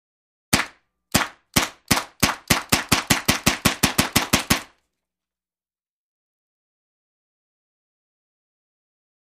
Paint ball Guns; Multiple Fires. Slowly, Then Increases To A Rapid Fire.